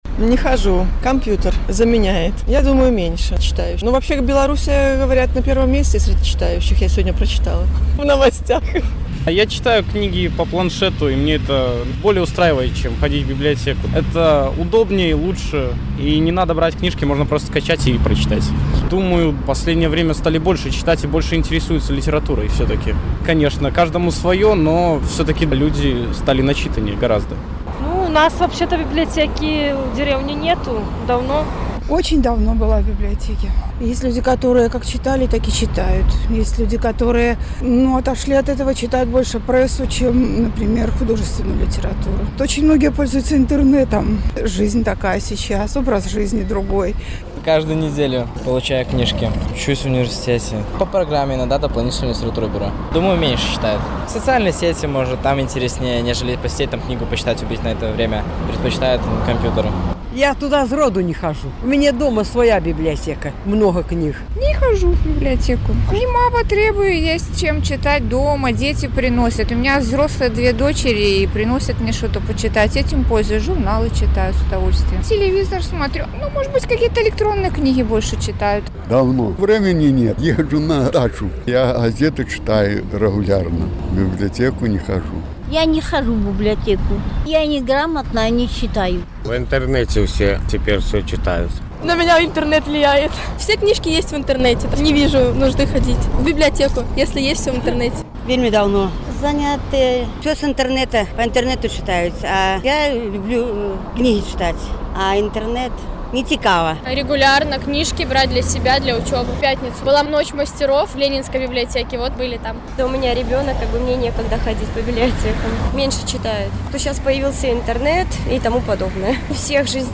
Ці даўно вы наведвалі бібліятэку? Адказваюць жыхары Гомеля